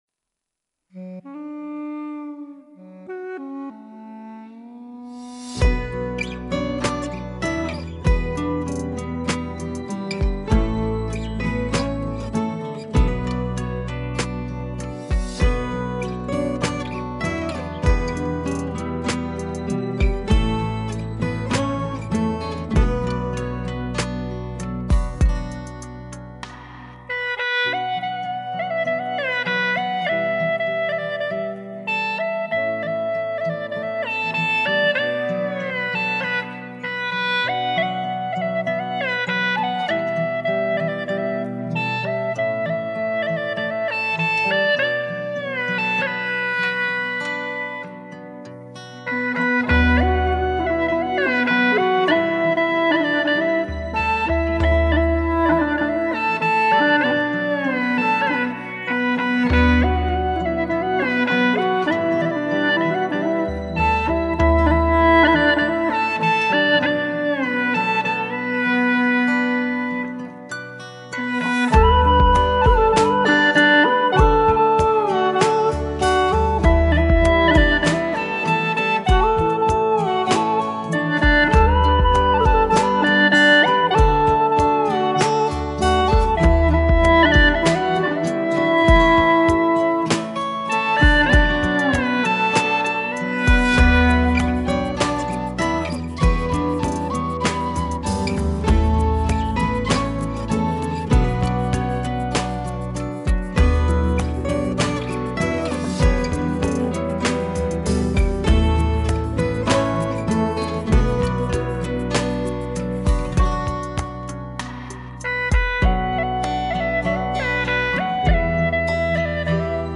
调式 : D 曲类 : 流行